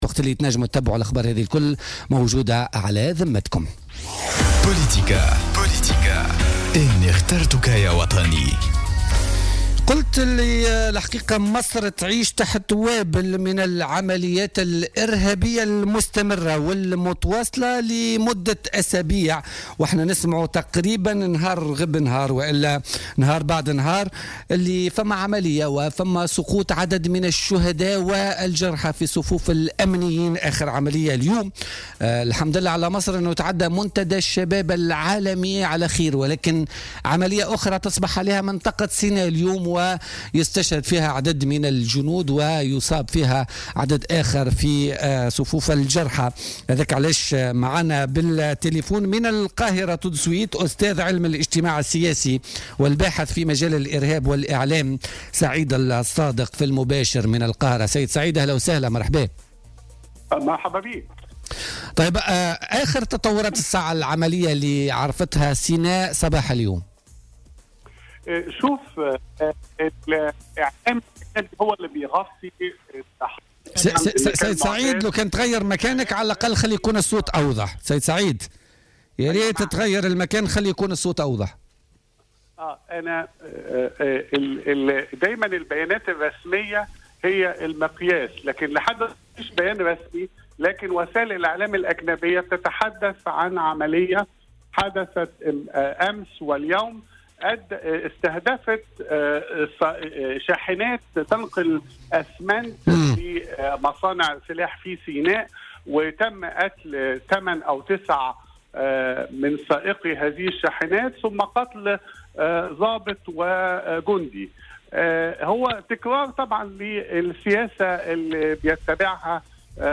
مداخلة له في بولتيكا